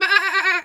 pgs/Assets/Audio/Animal_Impersonations/sheep_2_baa_high_06.wav at master
sheep_2_baa_high_06.wav